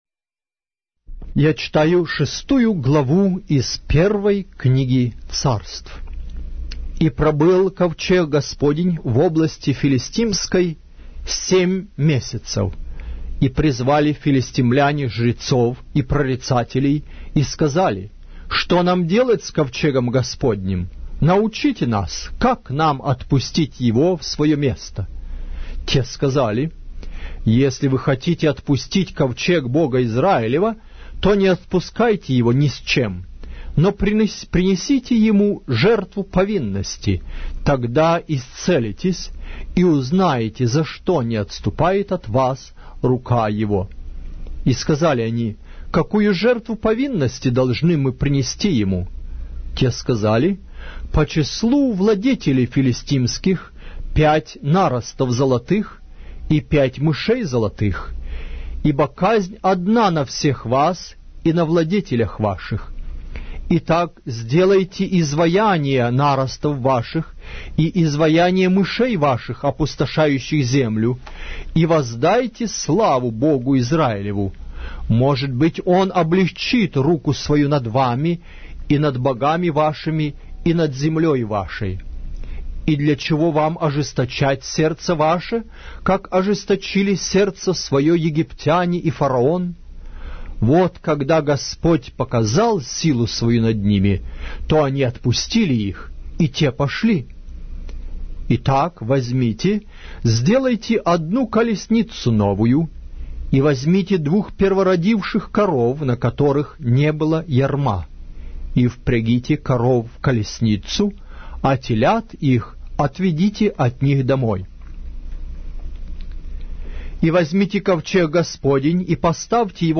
Глава русской Библии с аудио повествования - 1 Samuel, chapter 6 of the Holy Bible in Russian language